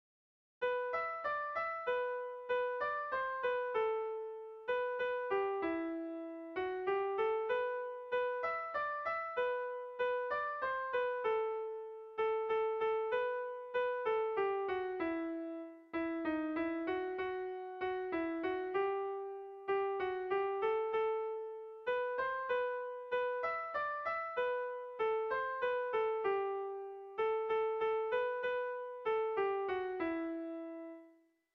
Zortziko handia (hg) / Lau puntuko handia (ip)
A1A2BA2